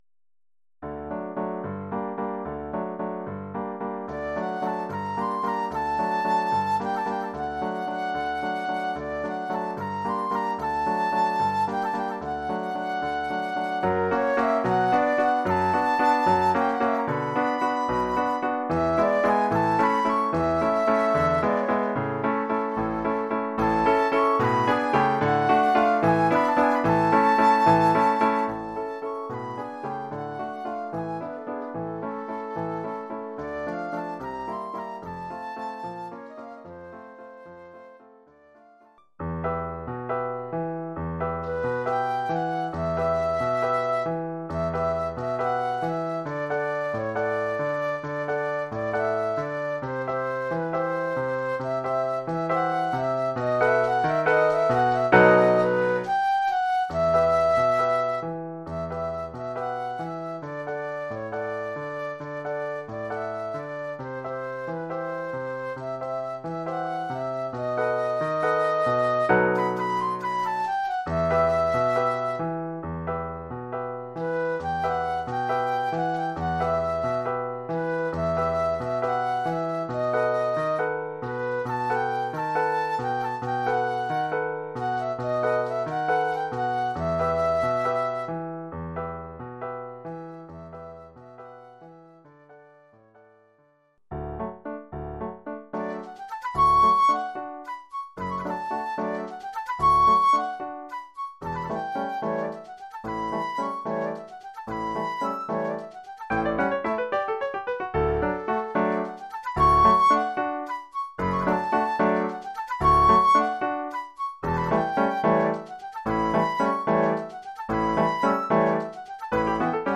1 titre, flûte et piano : conducteur et partie de flûte
Oeuvre pour flûte et piano.